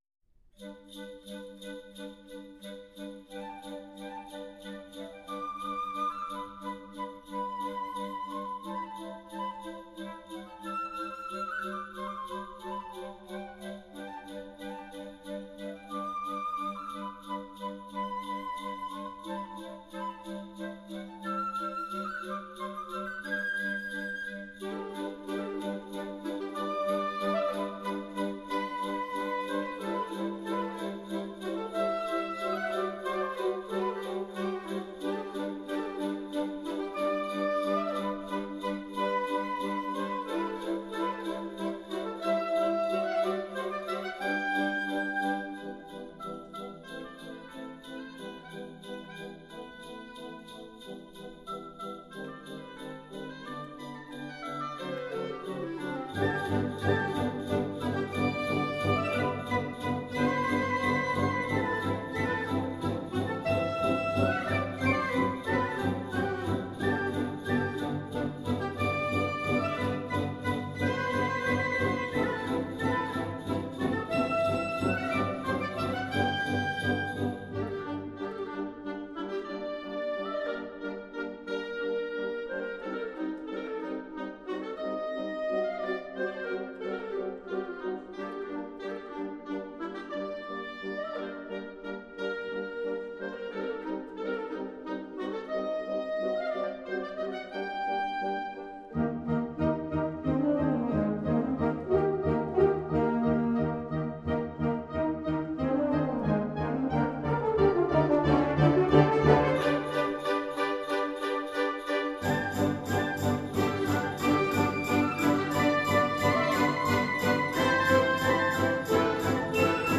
Genre: Band
1st/2nd Flute
Euphonium
Tuba
Timpani